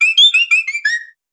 Cri de Plumeline Style Buyō dans Pokémon Soleil et Lune.